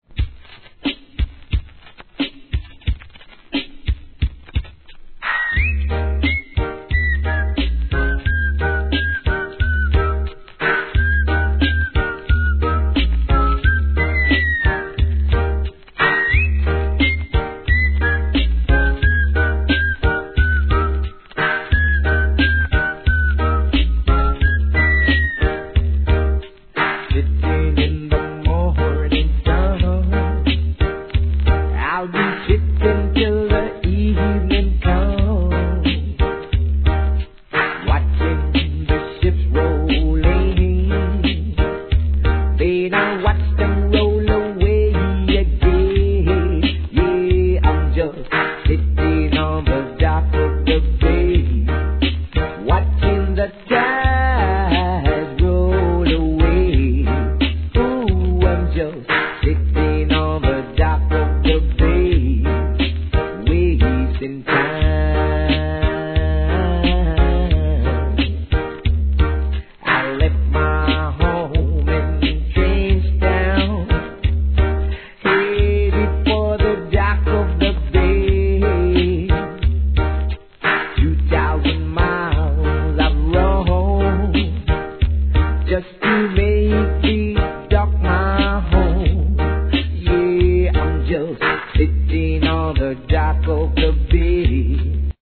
REGGAE
口笛が印象的な